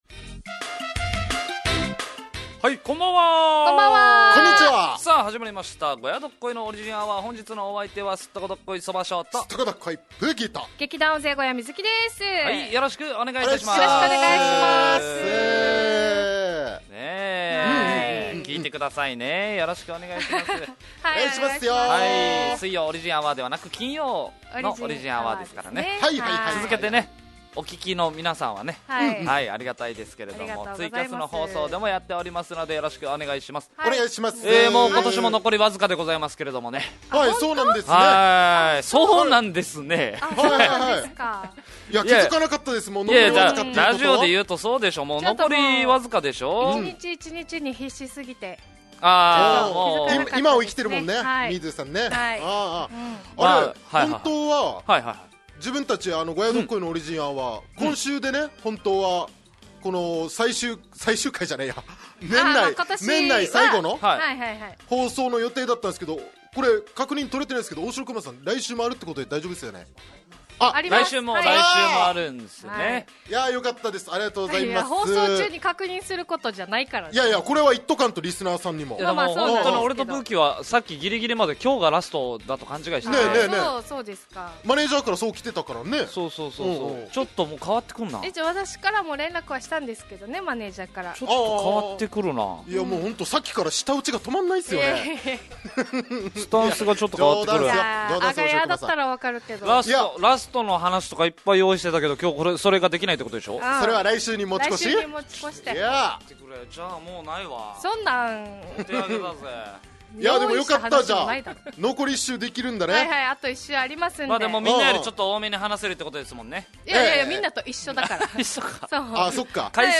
fm那覇がお届けする沖縄のお笑い集団・オリジンメンバー出演のバラエティ